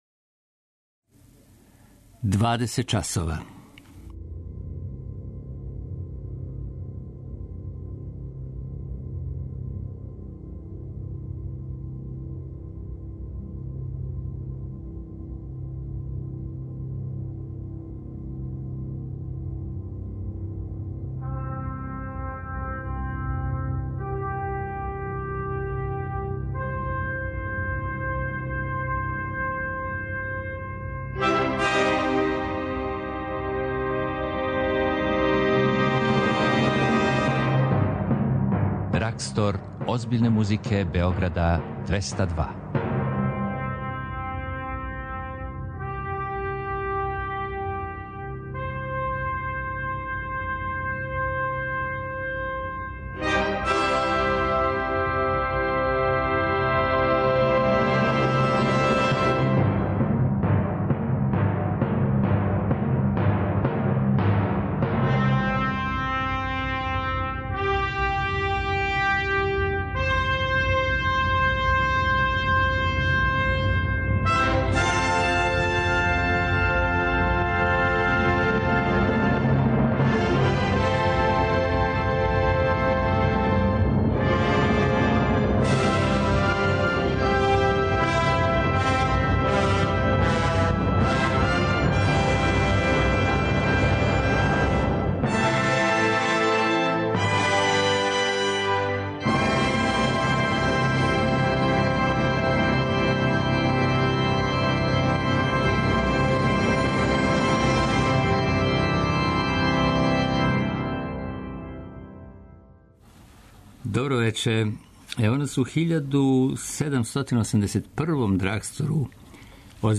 Најџел Кенеди, Гидон Кремер, Марта Аргерич, Пласидо Доминго... само су нека од имена која ће у вечерашњој емисији изводити дела Вивалдија, Бетовена, Вердија... Биће речи и о 10. јубиларном фестивалу ране музике (ФЕРАМ) који почиње ове недеље и за који ћемо делити карте!